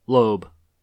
En-us-Loeb.oga.mp3